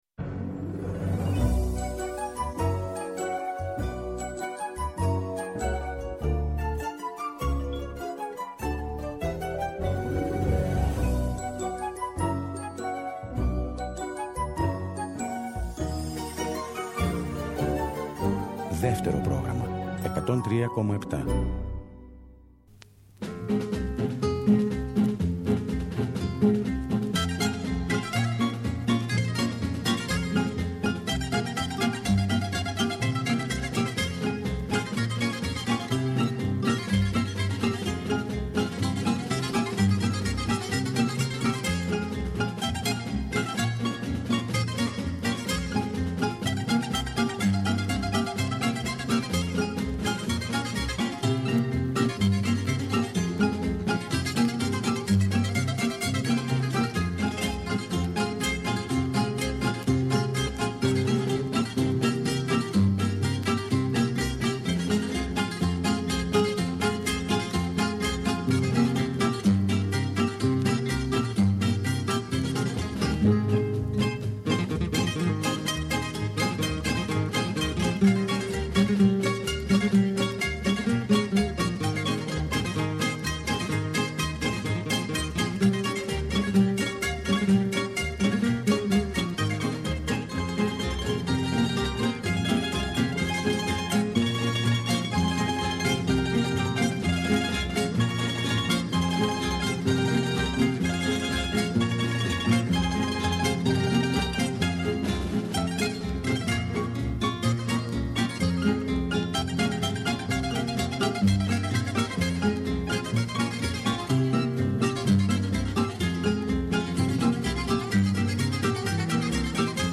Τί καλύτερο για το Σαββατόβραδο από μια εκπομπή με τραγούδια που αγαπήσαμε;